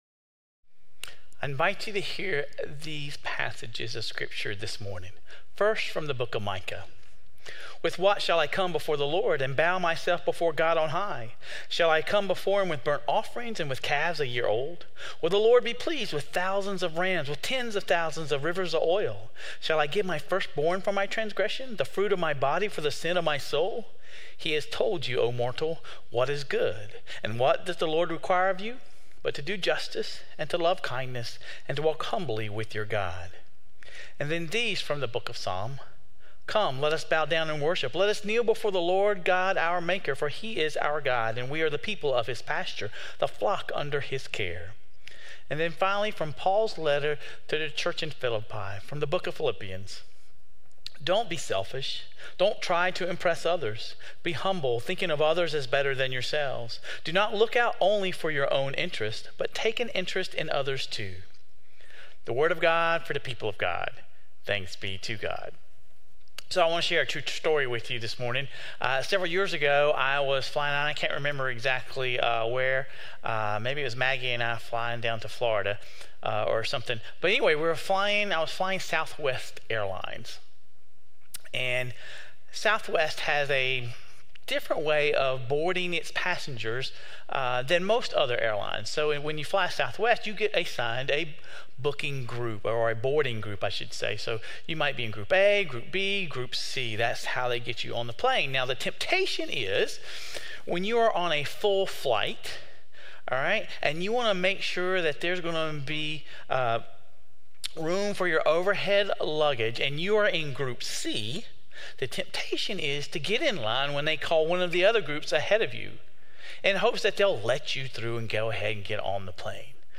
This week, we dig into what it means to “walk humbly with God.” Sermon Reflections: The sermon emphasized the connection between humility and serving others, citing Jesus washing the disciples' feet as an example.